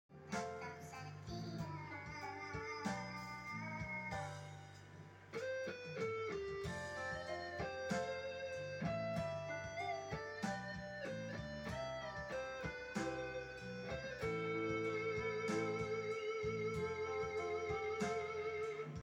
Sekali kali diperlihatkan proses pembuatan musik di MS MUSIC STUDIO KAL-TENG